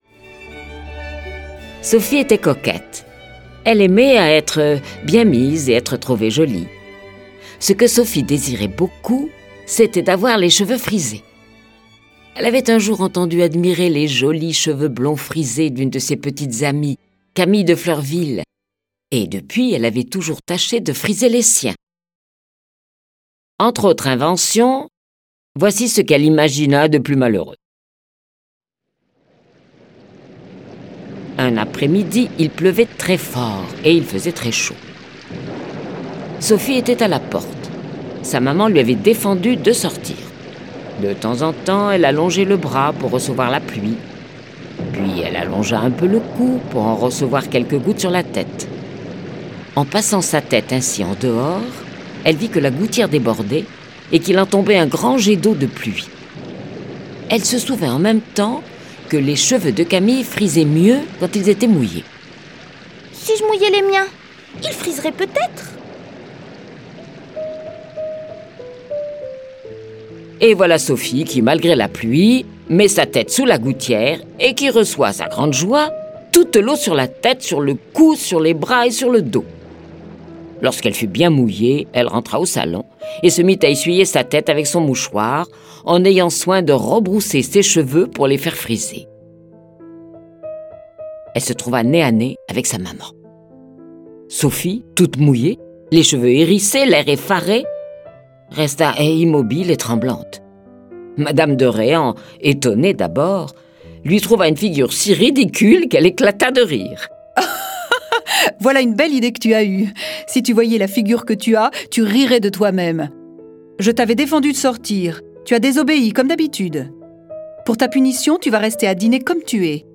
Cette version sonore des aventures de Sophie est animée par dix voix et accompagnée de près de trente morceaux de musique classique.